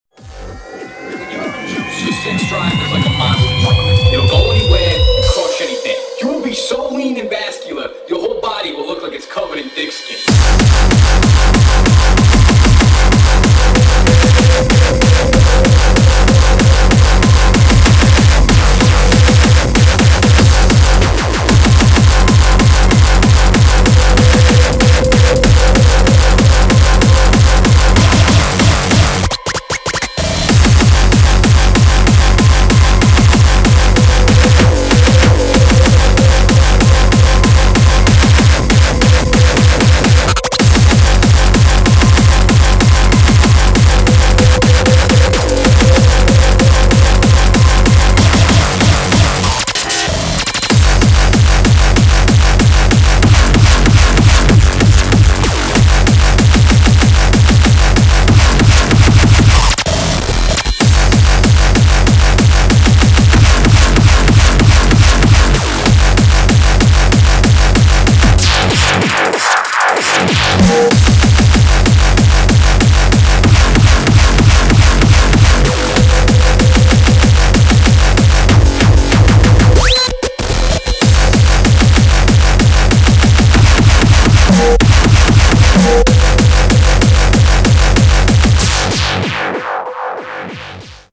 [ HARCORE ]